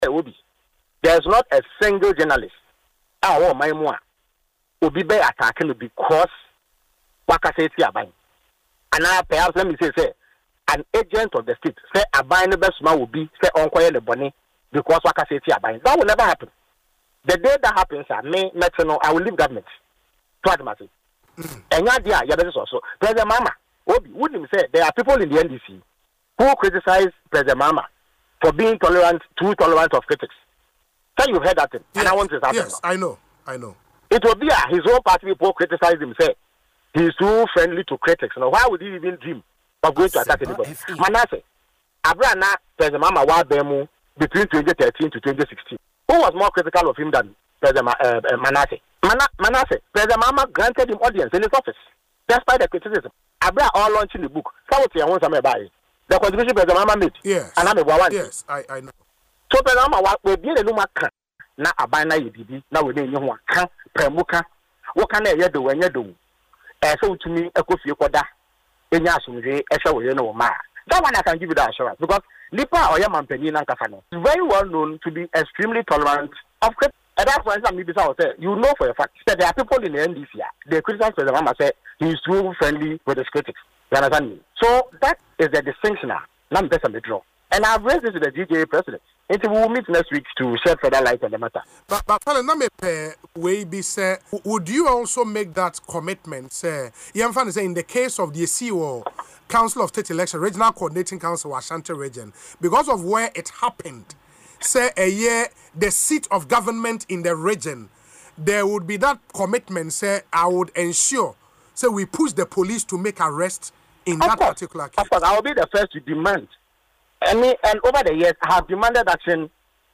Speaking in an interview on Asempa FM’s Ekosii Sen show, he assured that President Mahama’s administration would never target journalists over criticisms.